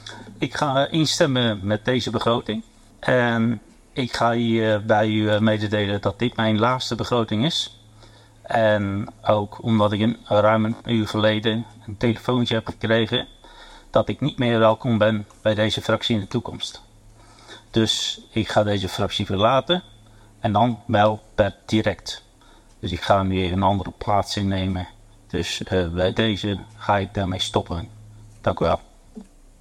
Audio: Arjan Deurloo kondigt tijdens de raadsvergadering zijn vetrek aan bij VVD Tholen.
“Ik ben niet meer welkom in de fractie”, zei hij, hoorbaar teleurgesteld.